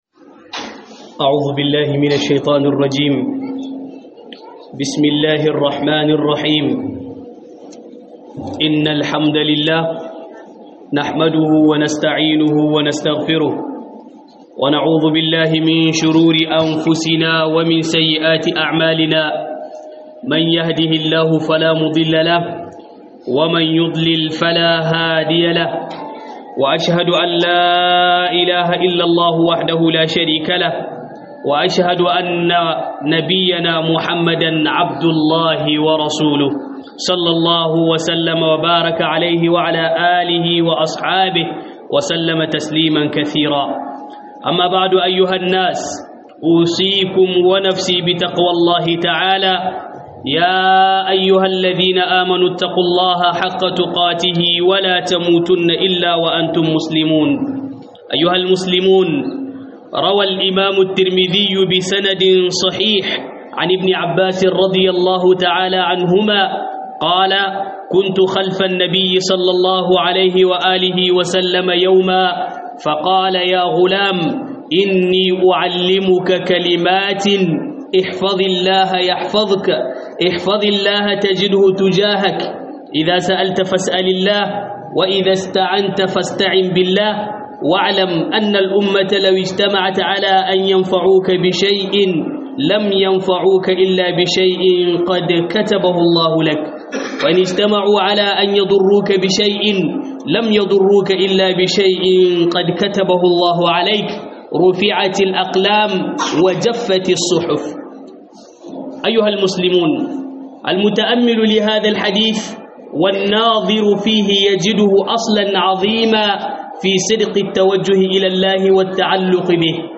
Huɗubar juma'a Da Al'umma zasu haɗu akanka - HUDUBA